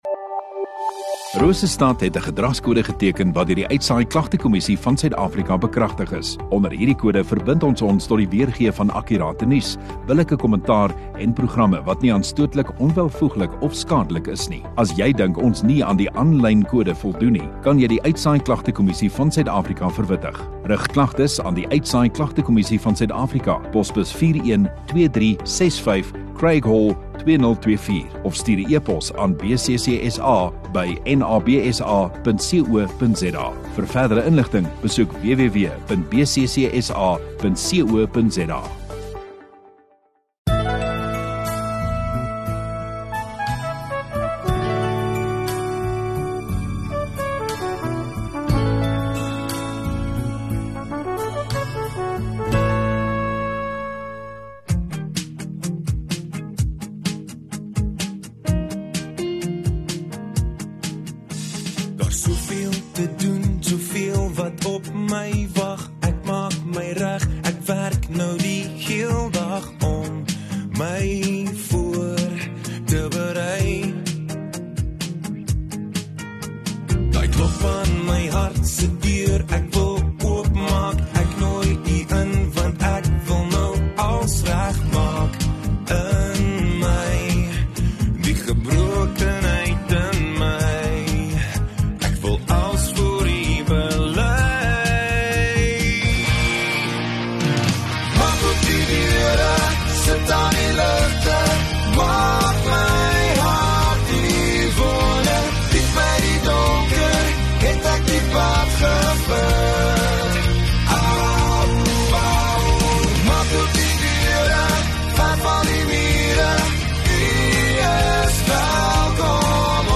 8 Mar Sondagaand Erediens